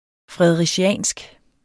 Udtale [ fʁεðʁεdˈɕæˀnsg ] eller [ fʁεðʁεˈɕæˀnsg ]